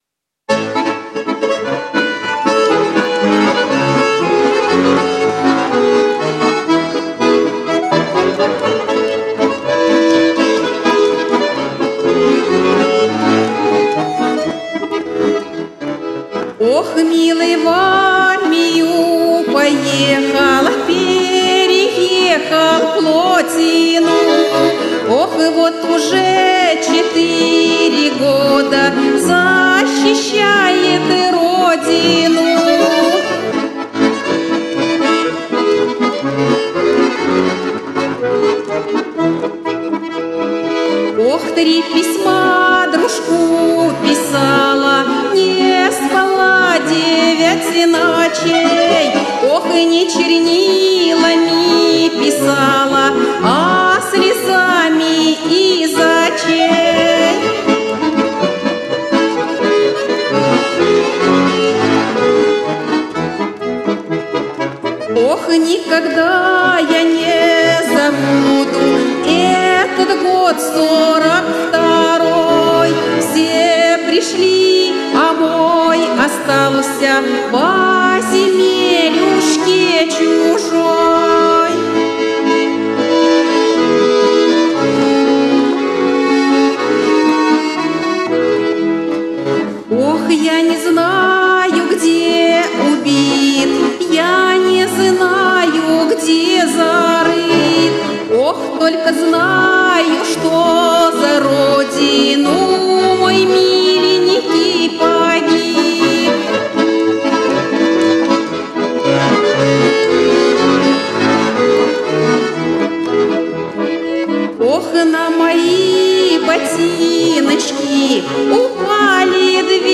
Песни военных лет в исполнении солистов и коллективов Рузского муниципального района
Частушки
13-Chastushki.mp3